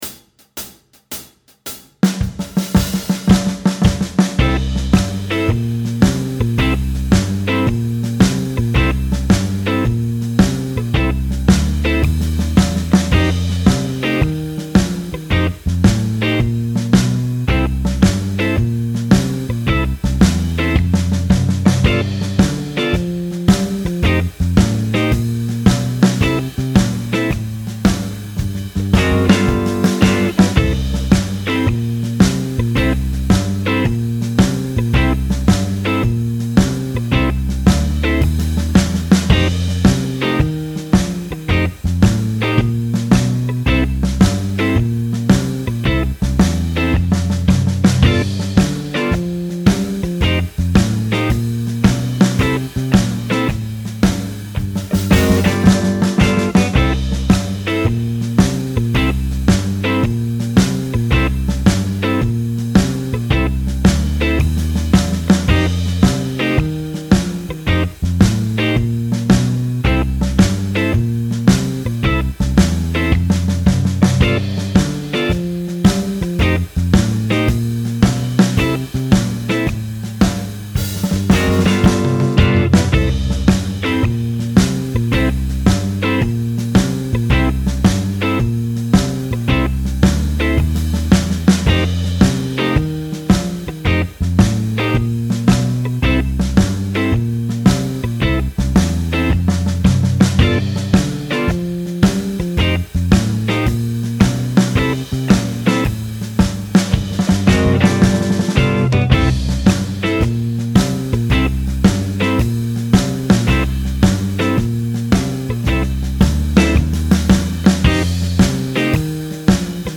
We continue our study of blues changes playing by looking at our scale choices for the iv and v chords. Again, there are plenty of example licks that you can add to your blues vocabulary.